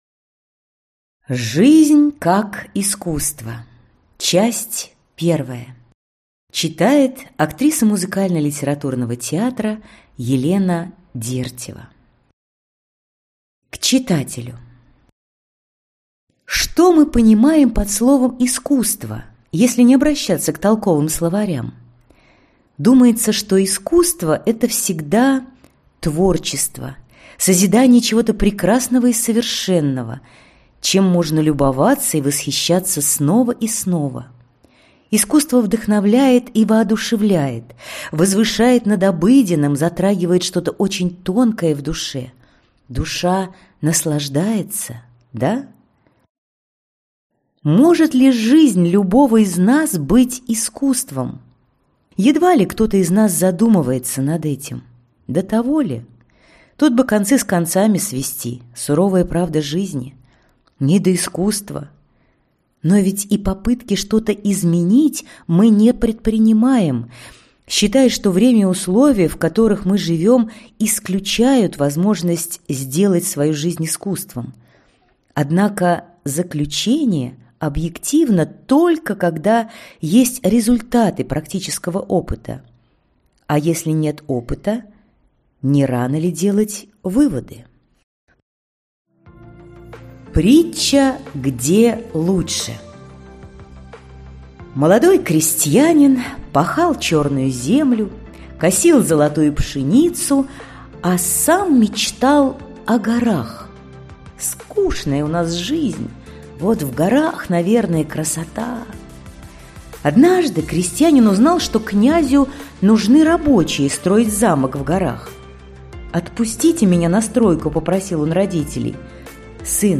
Аудиокнига Жизнь как искусство. Часть 1 | Библиотека аудиокниг